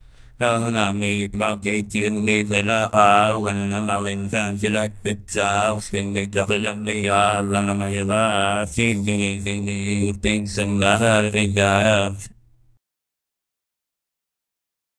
Free AI Sound Effect Generator
The man says "Tung tung tung sagor"
the-man-says-tung-tung-glmoncov.wav